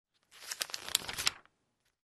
На этой странице собраны звуки журналов: шелест страниц, перелистывание, легкие постукивания обложки.
Звук перелистывания страниц журнала